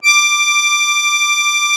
MUSETTE 1.18.wav